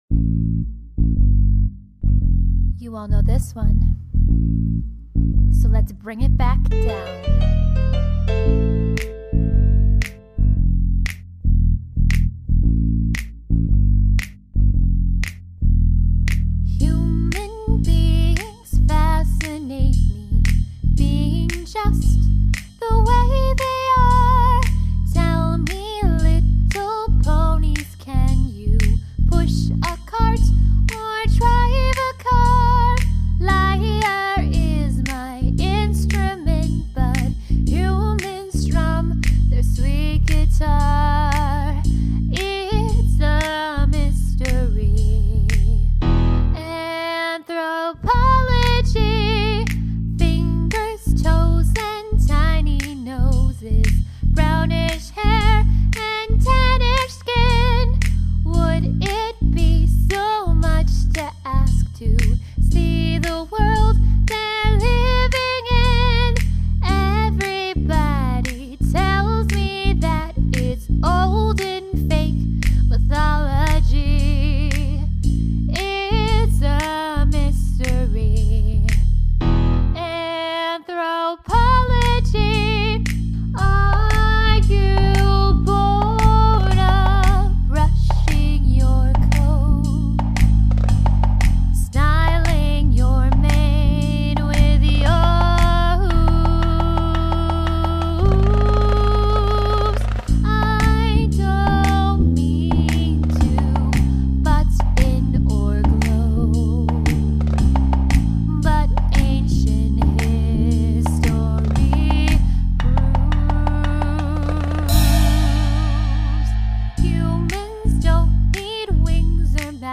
genre:jazz